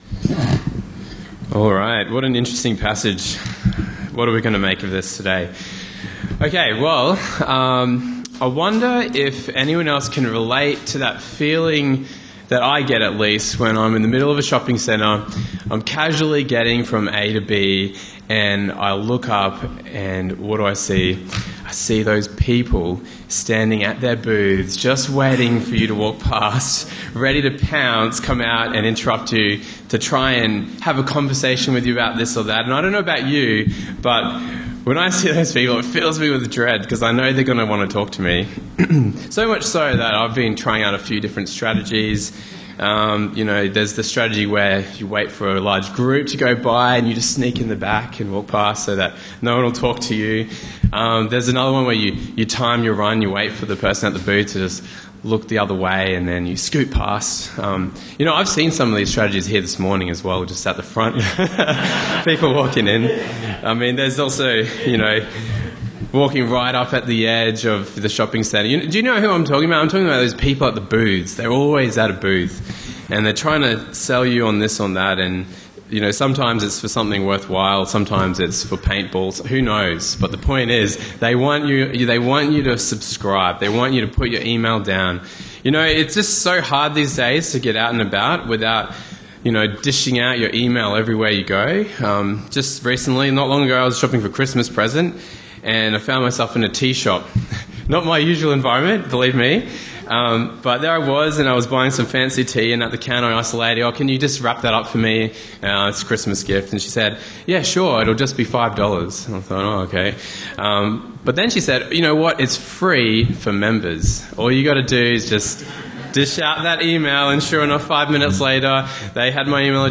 Bible Talks Bible Reading: Matthew 8:18-34